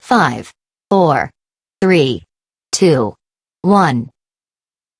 countdown.wav